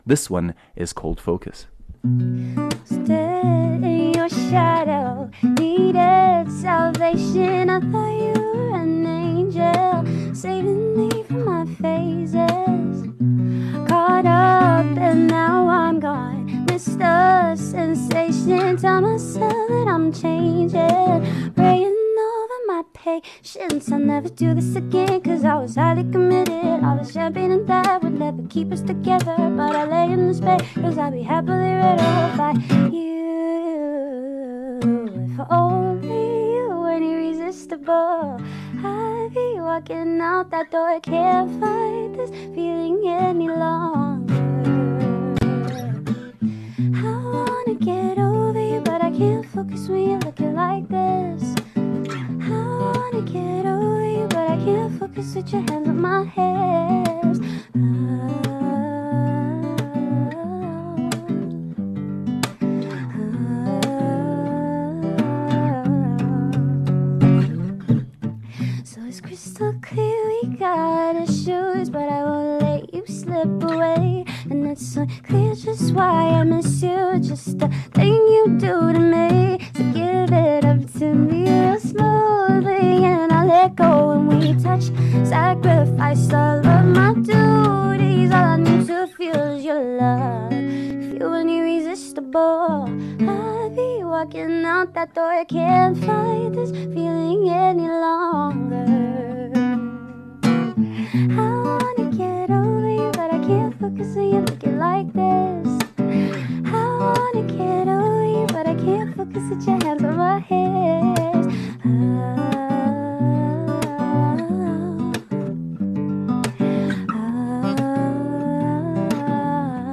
joins us live in studio performing her new single